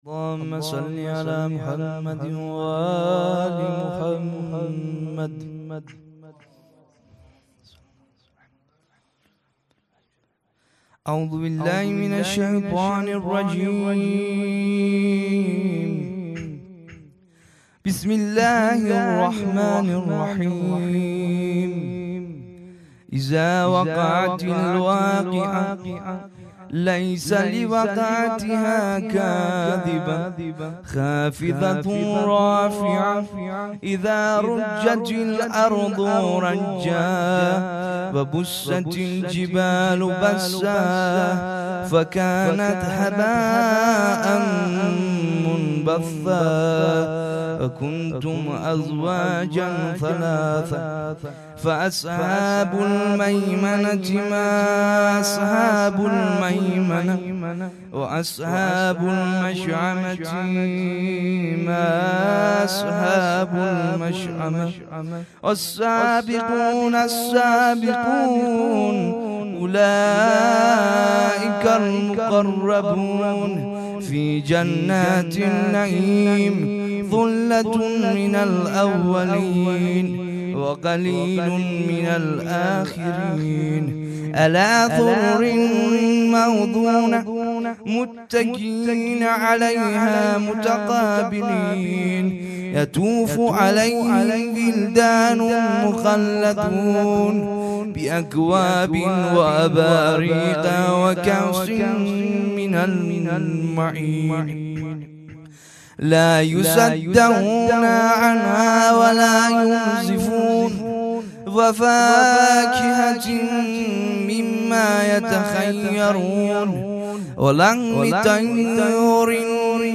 خیمه گاه - هیئت بچه های فاطمه (س) - قرائت قرآن
هیئت مجازی:جلسه ویژه شهادت امام کاظم(ع)